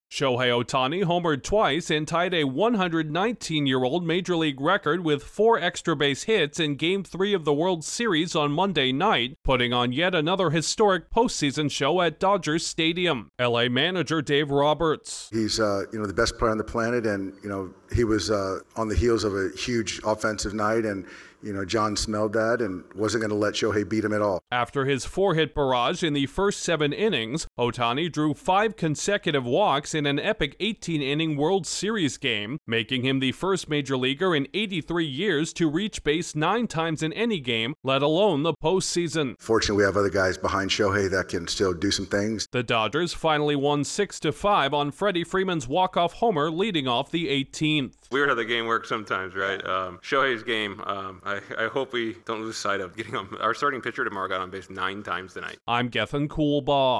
Baseball’s biggest star had another performance for the ages on the sport’s biggest stage. Correspondent